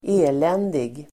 Uttal: [²'e:len:dig]